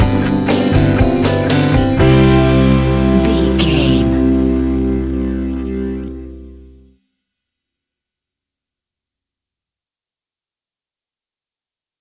Ionian/Major
fun
energetic
uplifting
cheesy
instrumentals
guitars
bass
drums
piano
organ